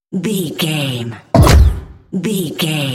Dramatic monster hit
Sound Effects
Atonal
heavy
intense
dark
aggressive